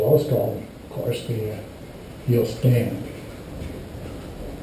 We recorded a carpentry workshop using the GoPro Hero2 camera and are putting it on a DVD to share with others.
I have run the audio thru Chris’s Audacity audio compressor and that seems to help bring up low volume parts (we had to film with the camera in it’s H2O case because of wind noise) but it is still not very clear with lots of back ground noise.
It sounds like your main problem is high levels of background noise ,
I suppose I could try the Audacity noise reduction effect but the voice is not very clear.
IMO applying equalization which cuts the bass makes it sound a bit less muffled …